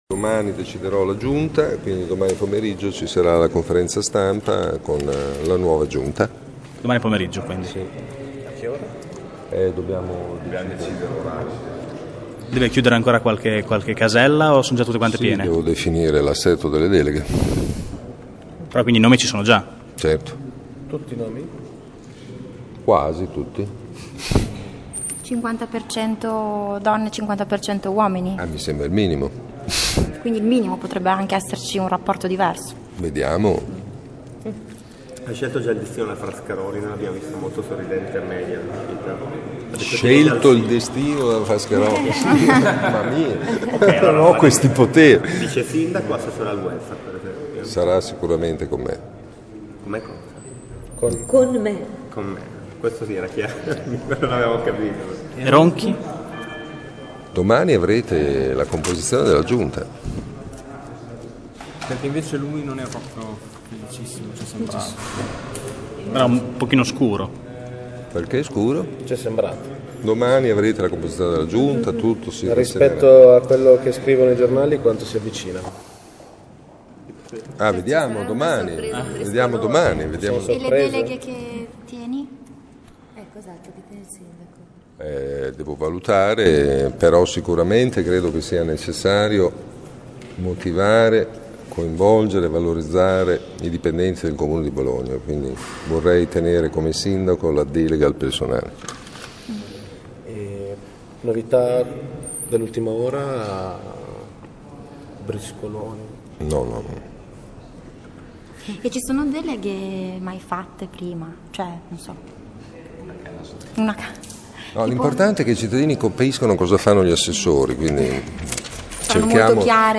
Lo ha dichiarato ai nostri microfoni Virginio Merola, sindaco di Bologna reduce dall’insediamento di ieri mattina. L’elenco è quasi pronto ha assicurato il sindaco che è impegnato in queste ore nella ripartizione delle deleghe.